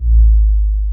808-Kicks40.wav